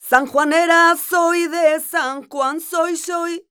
46a07voc-a#m.wav